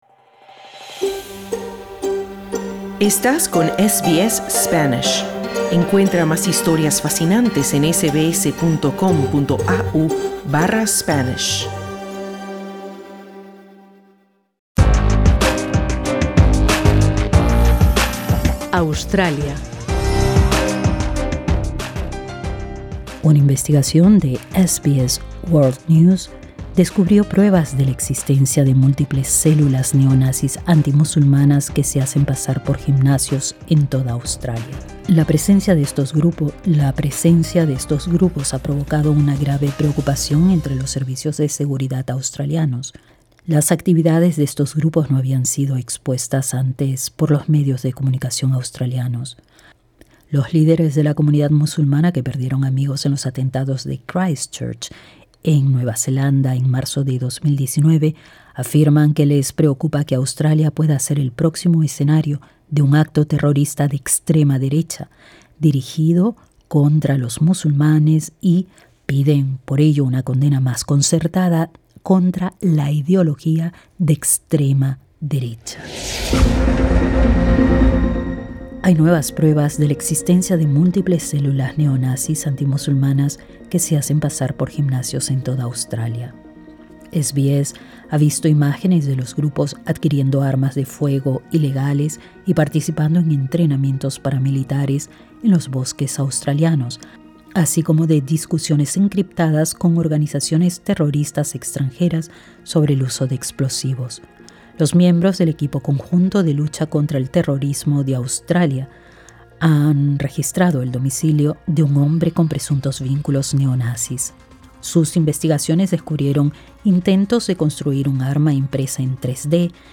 Ahora es la amenaza que más crece en el país. Lee y escucha el informe.